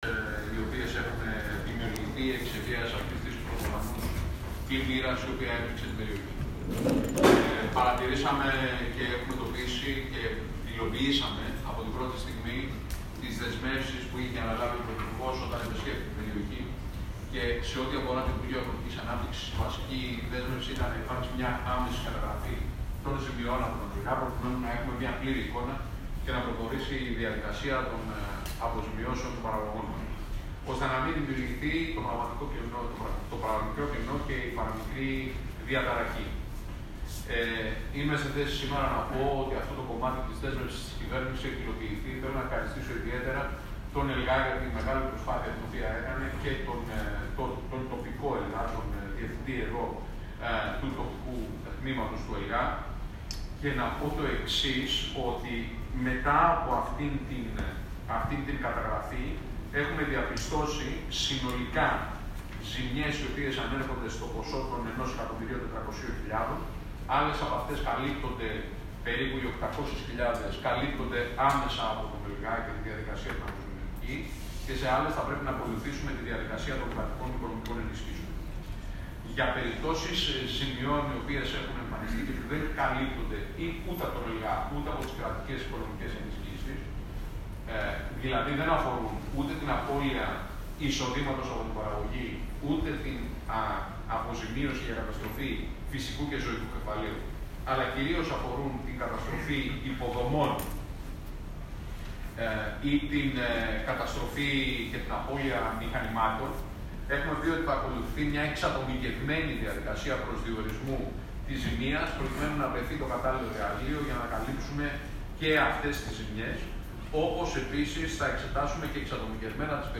Αποκλειστικά οι δηλώσεις Βορίδη,Σταϊκούρα, και Σπανού μετά τη σύσκεψη στο διοικητήριο της Χαλκίδας [ηχητικό]
Ακούστε αποκλειστικά τι δήλωσαν ο Μάκης Βορίδης, ο Χρήστος Σταικούρας και ο Φάνης Σπανός.